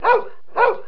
دانلود صدای حیوانات جنگلی 79 از ساعد نیوز با لینک مستقیم و کیفیت بالا
جلوه های صوتی